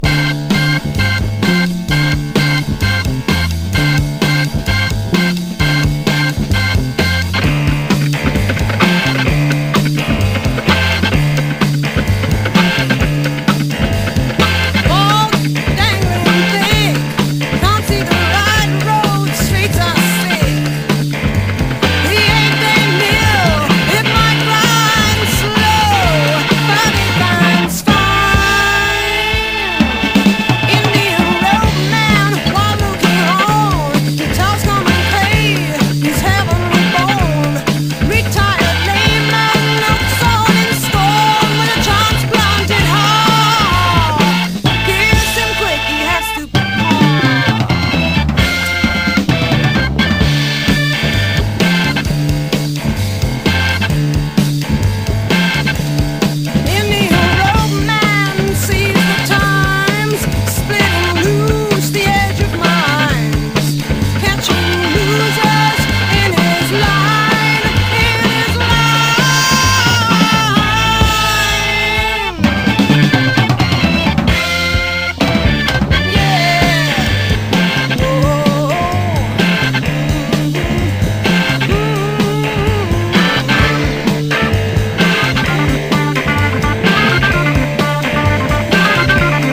ROCK / 60'S / GARAGE PUNK / FREAK BEAT / PSYCHEDELIC (UK)
これぞフリーク・ビート！な凶暴ブリティッシュ・サウンド！